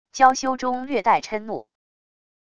娇羞中略带嗔怒wav音频